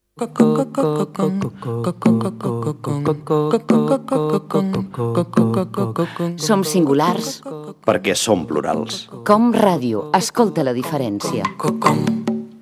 8c463c66b078ebe5ec008f511ca1d67bce7e0c20.mp3 Títol COM Ràdio Emissora COM Ràdio Barcelona Cadena COM Ràdio Titularitat Pública nacional Descripció Indicatiu "Escolta la diferència".